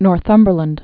(nôr-thŭmbər-lənd)